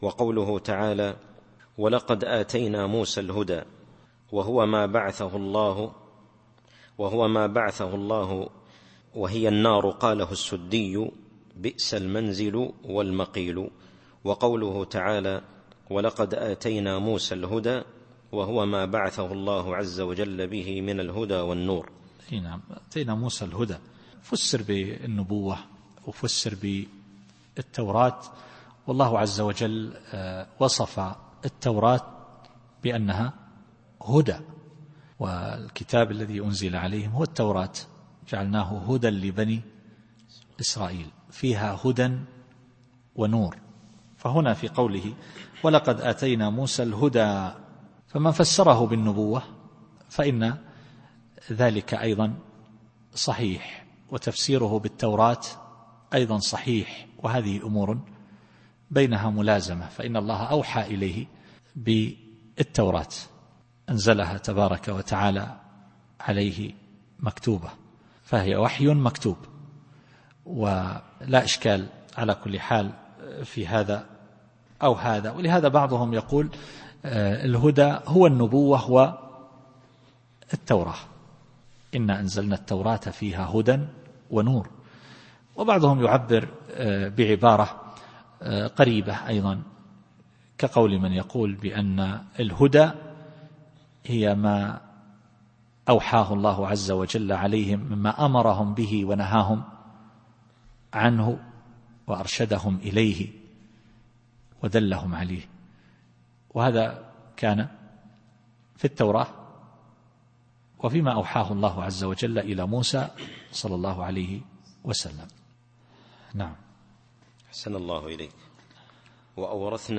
التفسير الصوتي [غافر / 53]